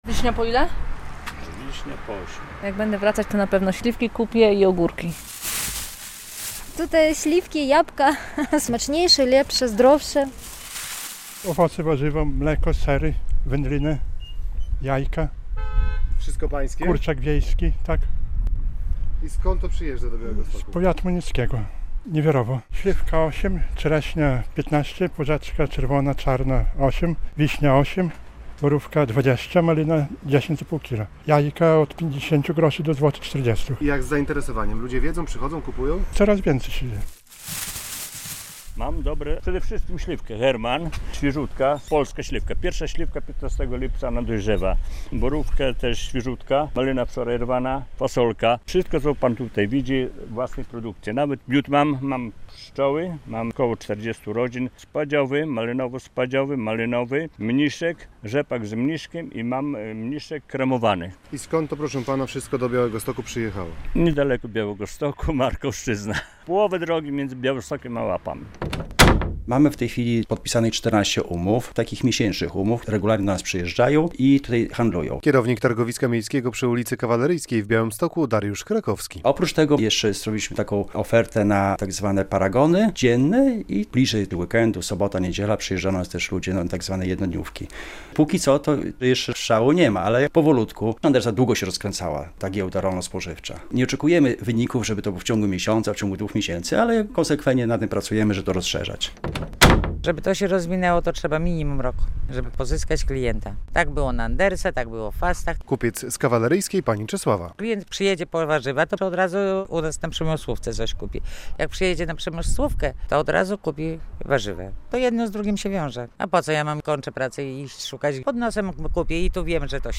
Ryneczek przy ulicy Kawaleryjskiej - relacja